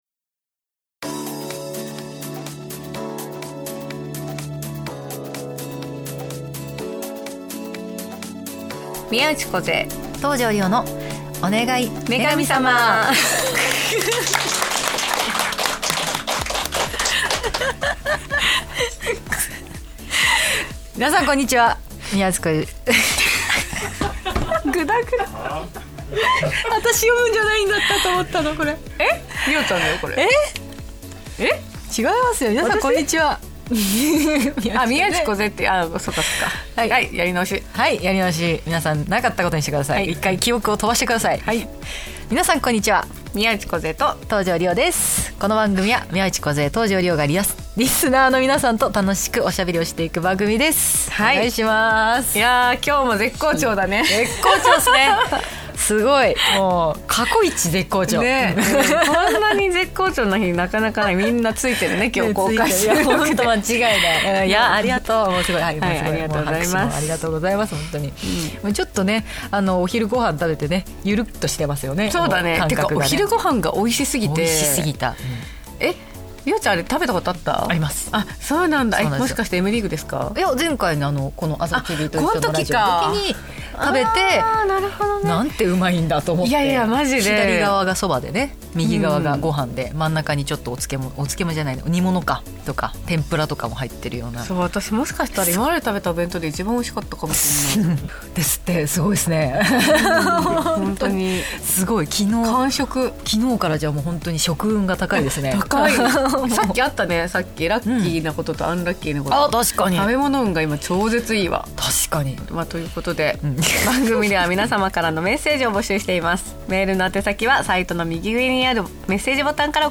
公開収録後半の部です！おふたりがカラオケで絶対に歌う歌とは・・！？